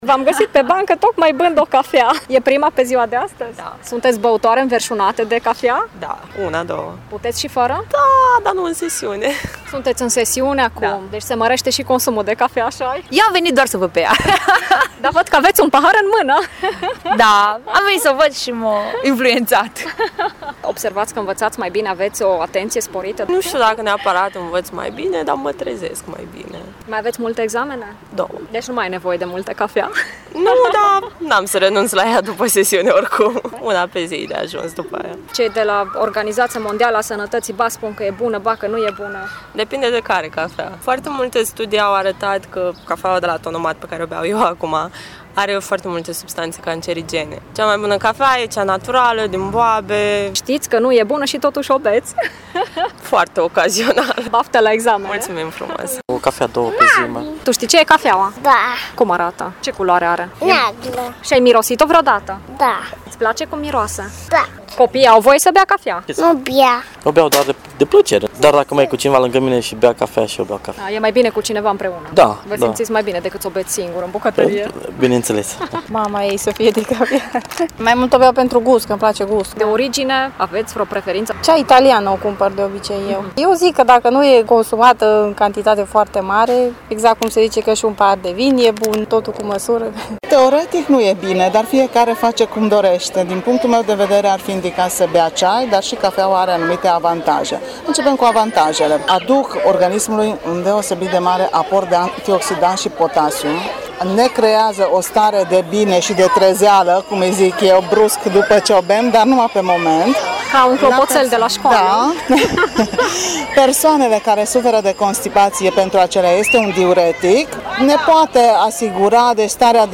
Reportaj-cafea.mp3